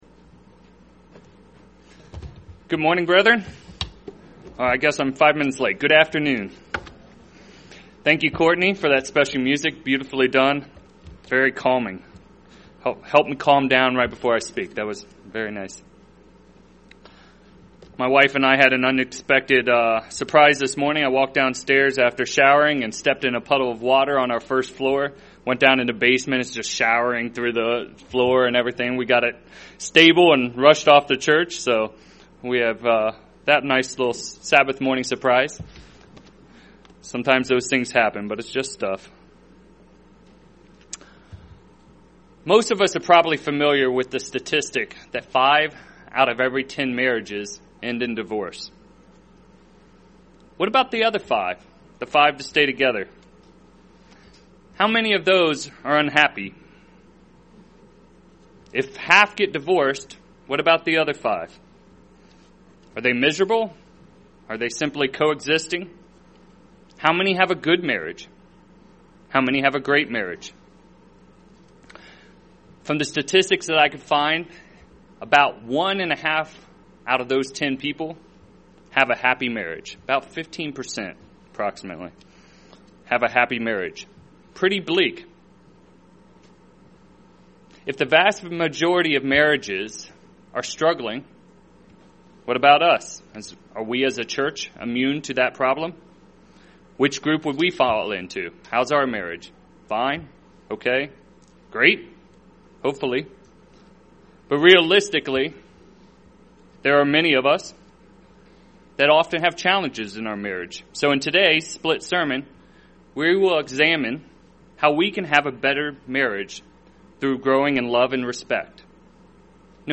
UCG Sermon Studying the bible?
Given in Cincinnati East, OH